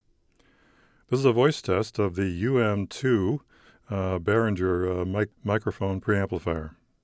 The Neewer is a side-fire microphone.
I didn’t make any soundproofing changes, so you may hear the cars on the street.